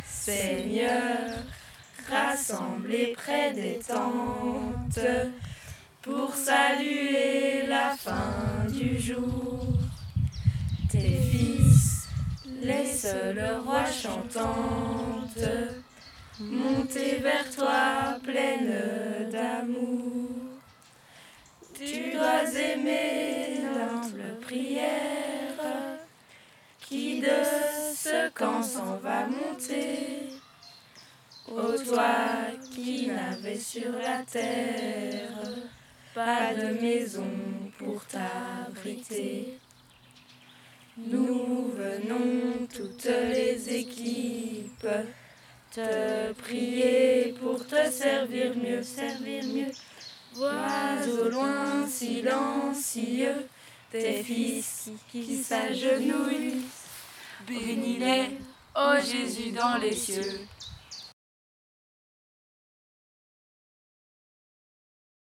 Genre : chant
Type : chant de mouvement de jeunesse
Interprète(s) : Patro de Bastogne
Lieu d'enregistrement : Bastogne
Il est chanté à la fin de la veillée.